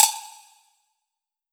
pbs - reggae [ Perc ].wav